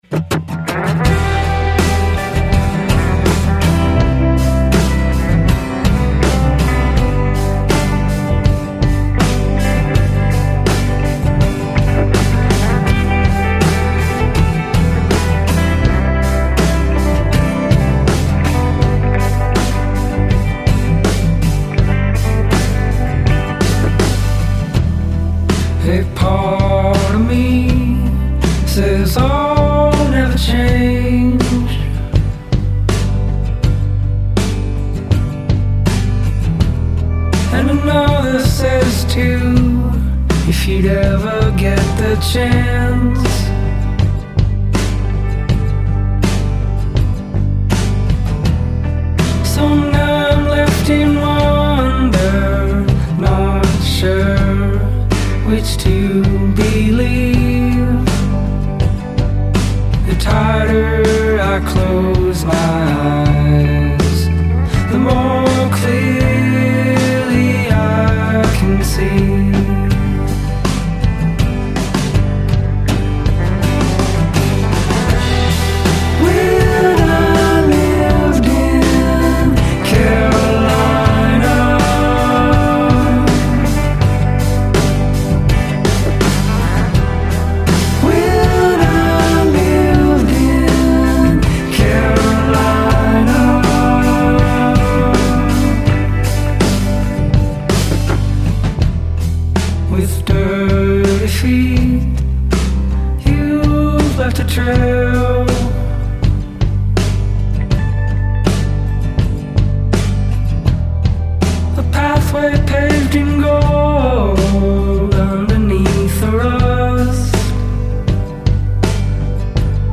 indie rock band